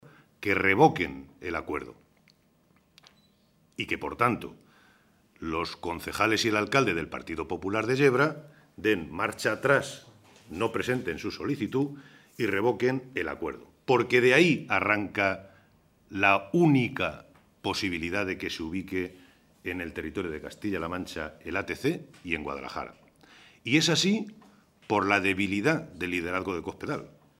Santiago Moreno, portavoz del Grupo Parlamentario Socialista
Cortes de audio de la rueda de prensa